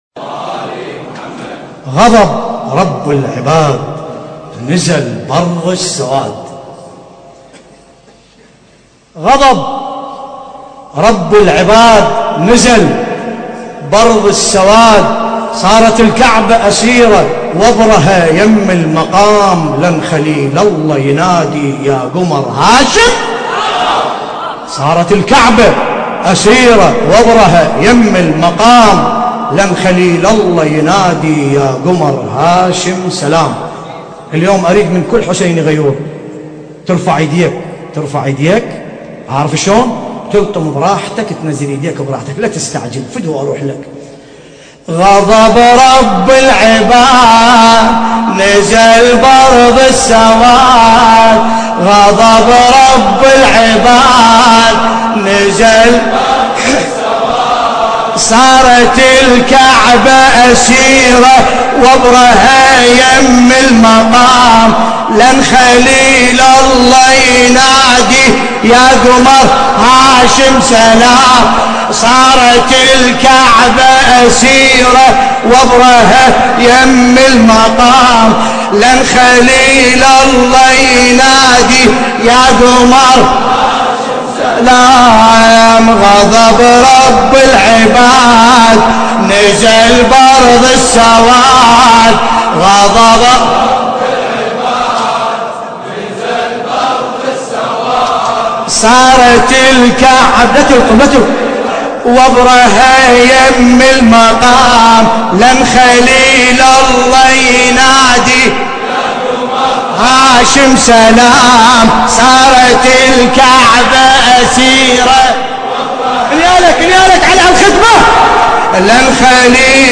القارئ: باسم الكربلائي التاريخ: الليلة السابع من شهر محرم الحرام عام 1434 هـ - الكويت .